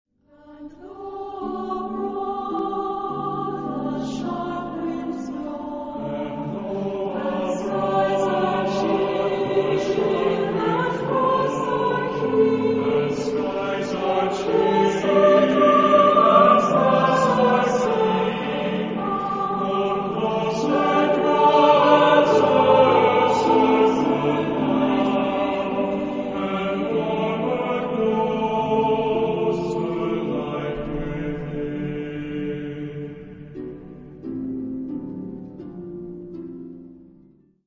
Género/Estilo/Forma: Sagrado ; Profano ; Canción de Navidad
Carácter de la pieza : dulce
Tipo de formación coral: SATB  (4 voces Coro mixto )
Instrumentación: Piano O arpa  (1 partes instrumentales)
Tonalidad : mayor